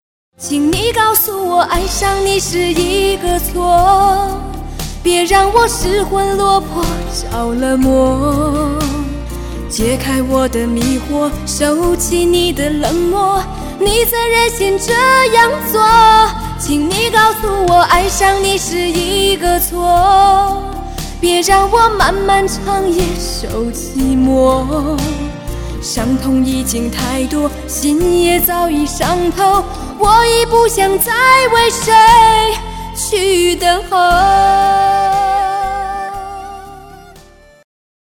MP3铃声